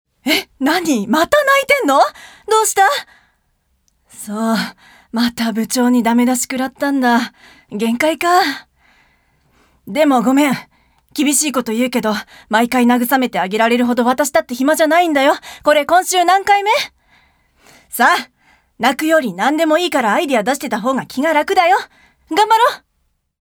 ボイスサンプル、その他
セリフ１